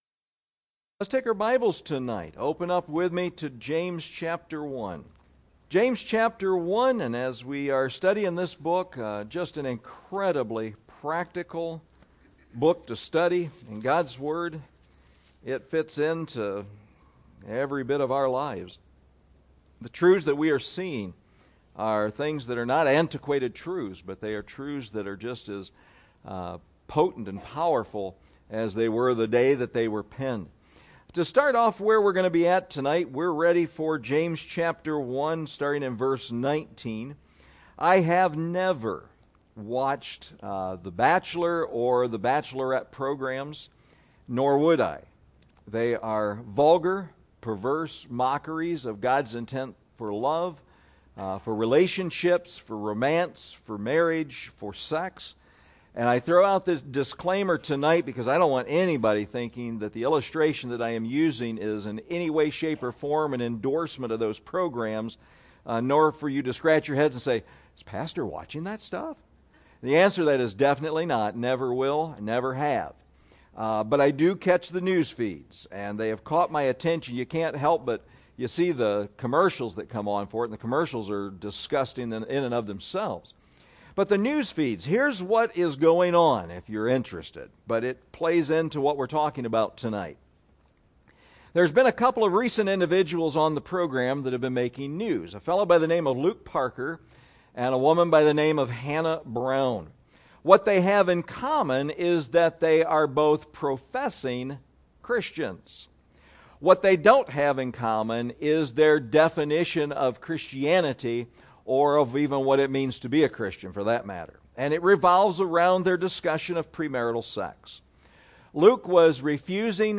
Christianitys-Credibility-Crisis-190721_PM_SERVICE.mp3